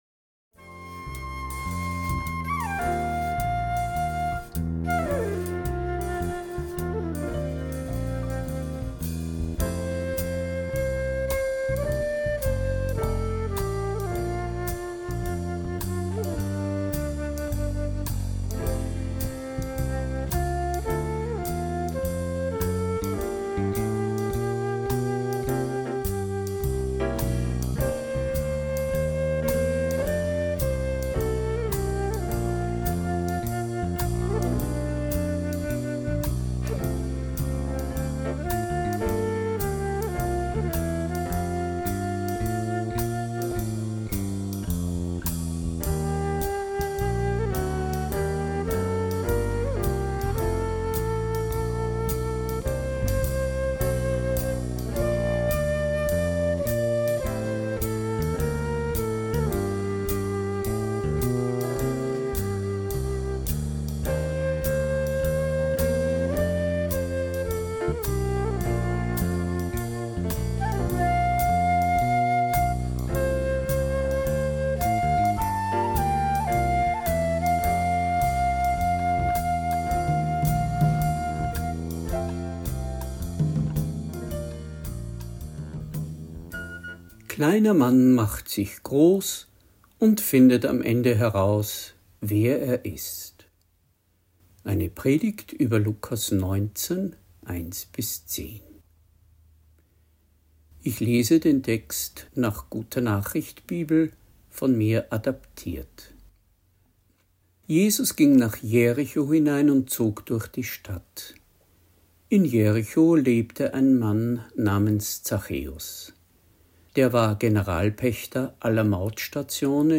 Predigt | NT03 Lukas 19,1-10 Kleiner Mann ganz groß – Glauben und Leben